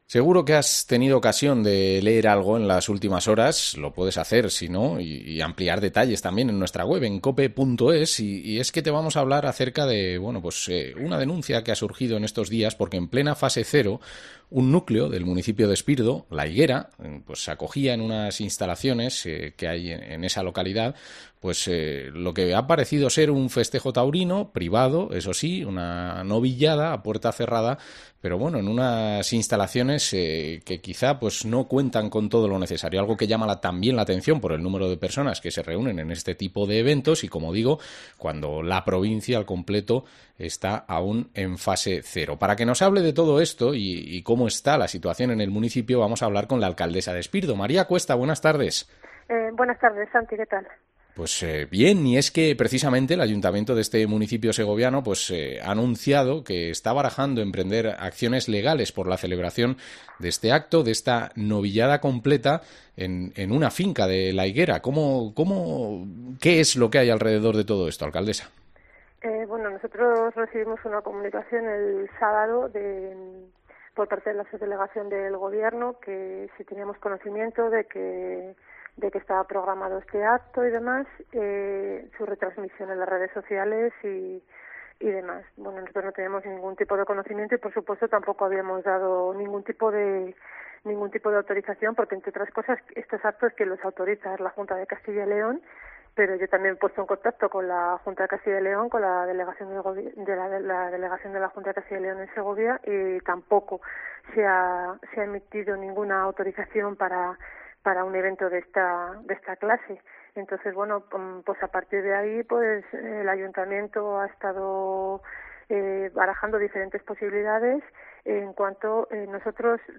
Entrevista a María Cuesta, alcaldesa de Espirdo